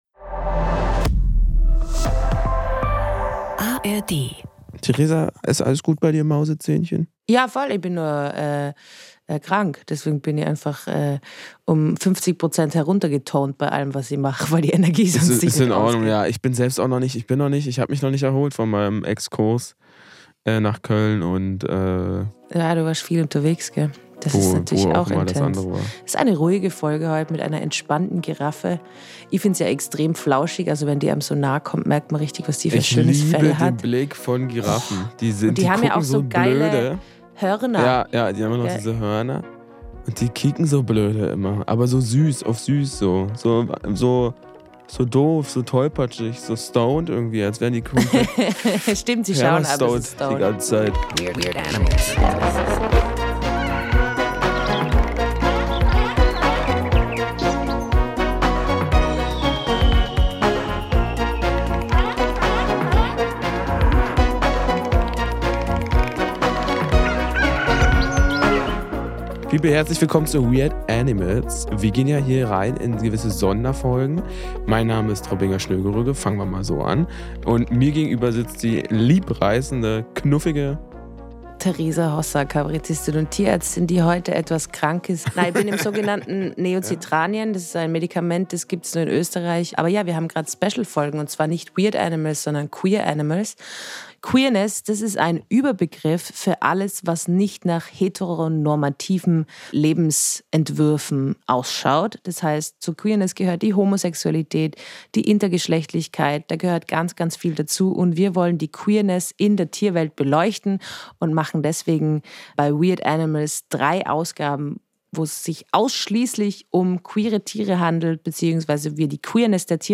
1 Fransenschildkröte - Das faulste Tier der Welt? (LIVE aus Berlin) 31:30